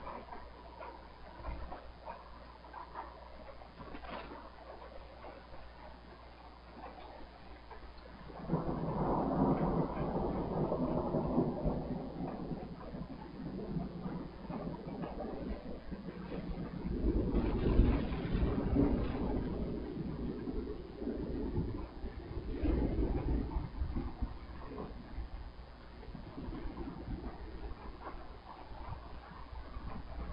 自然的声音 " 雷霆室内3
描述：记录从室内，雷鸣般的雷鸣。雷电雷雨天气雷雨滚滚雷声隆隆声
Tag: 闪电 天气 滚动雷 雷暴 雷暴 隆隆